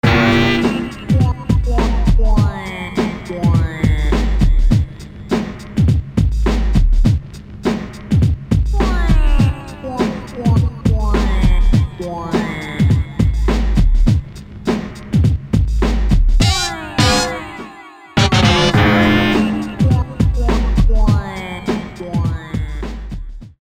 61 JJ's Theme Jumpy beat